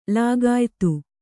♪ lāgāytu